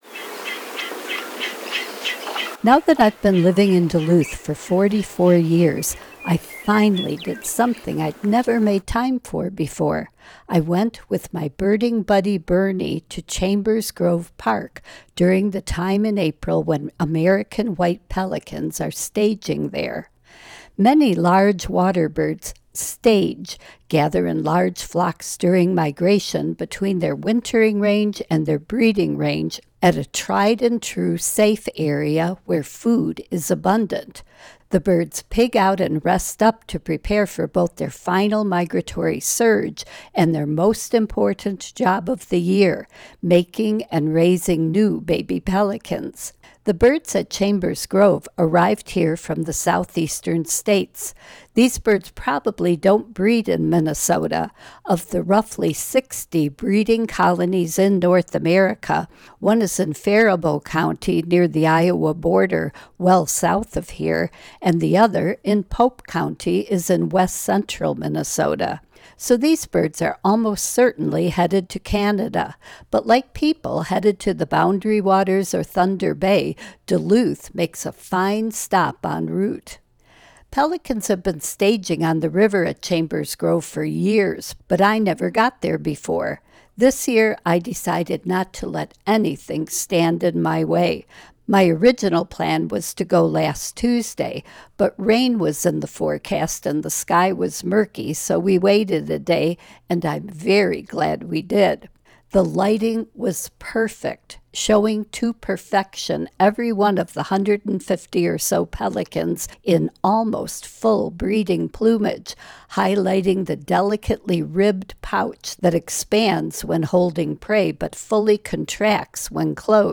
Pelicans_at_Chambers_Grove_Park.mp3